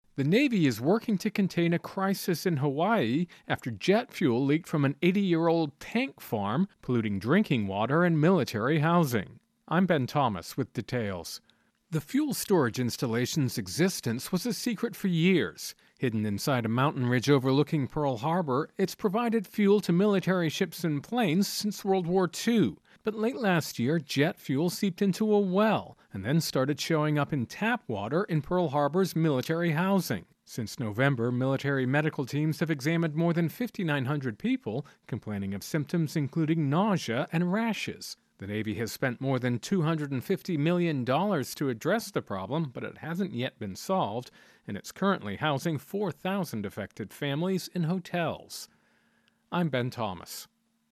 Intro and voicer "Hawaii-Tainted Water"